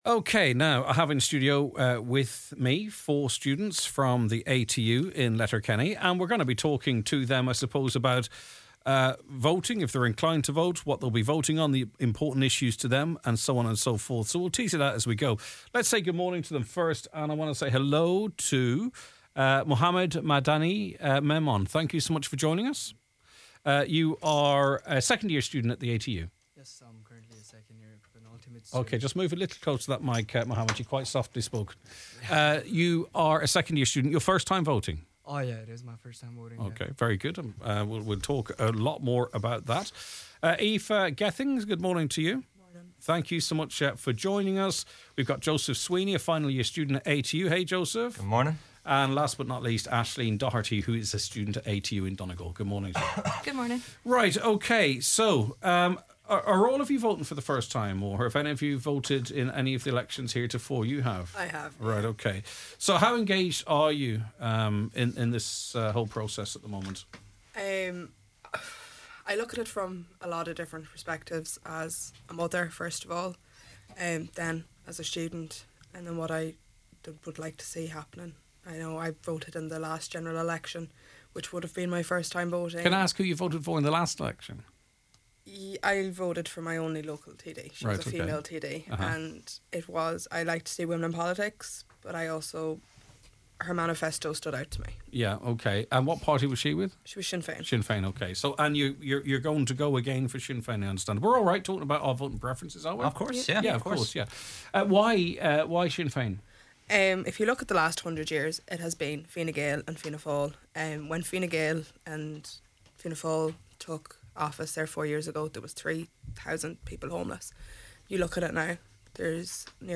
Today we talk to ATU students about the issues important to them as they prepare to cast their vote in the General Election, some for the first time.